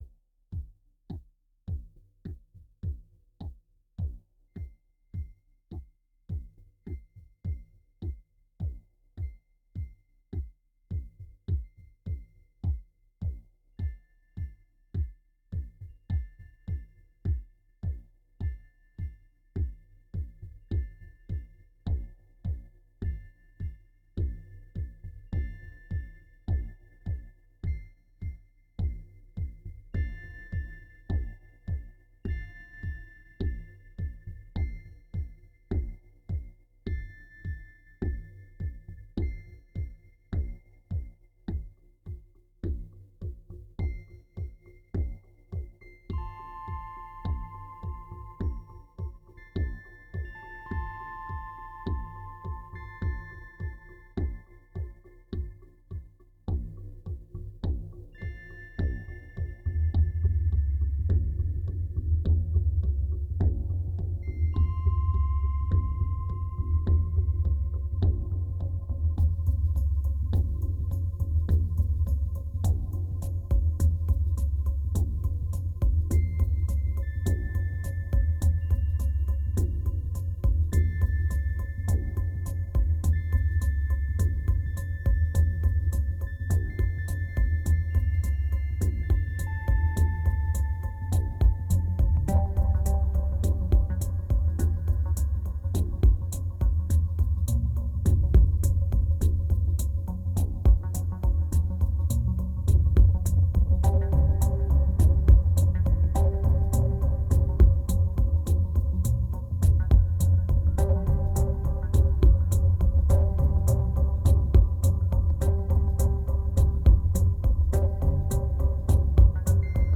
2287📈 - -10%🤔 - 104BPM🔊 - 2010-11-09📅 - -553🌟